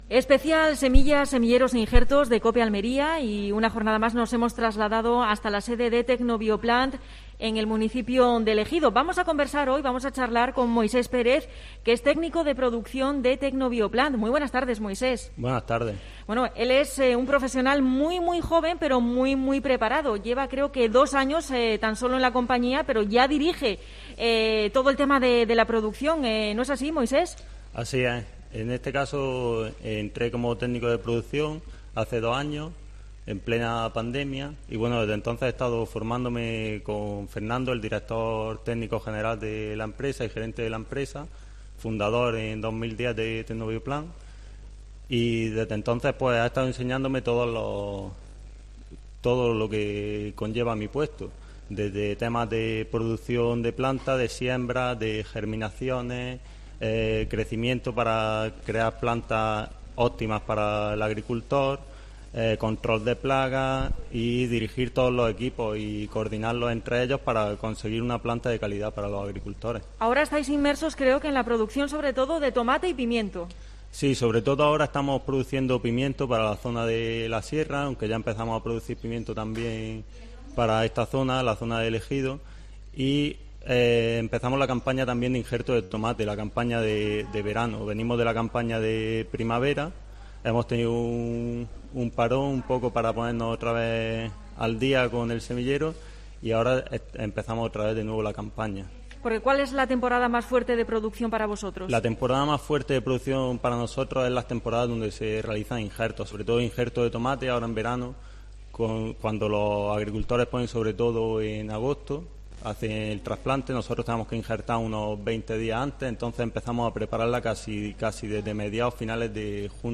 Última jornada de los programas especiales sobre 'semillas e injertos' desde Tecnobioplant.